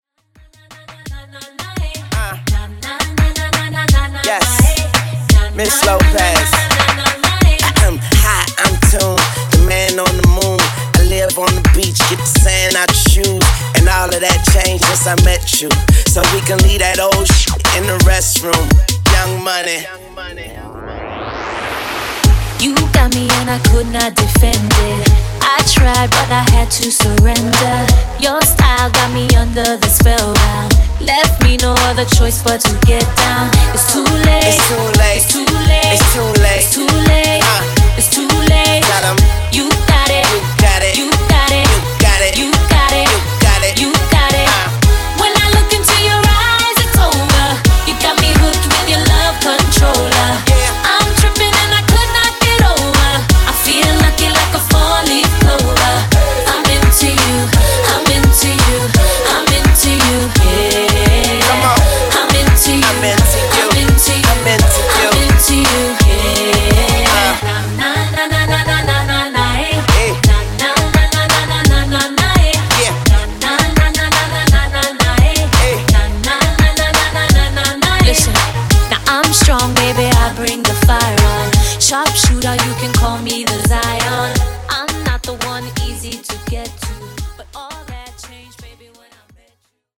Re-Drum)Date Added